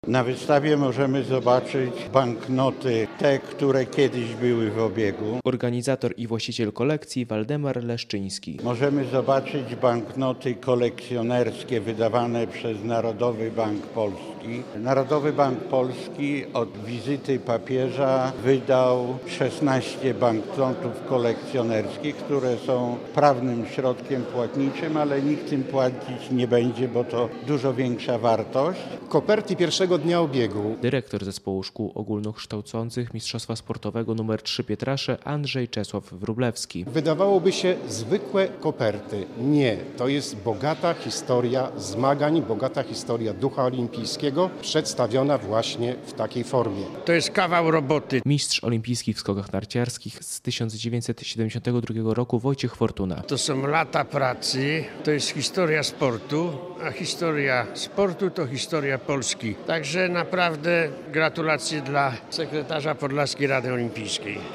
relacja
Mistrz olimpijski w skokach narciarskich z 1972 r. Wojciech Fortuna podkreśla, że sport buduje historię naszego kraju.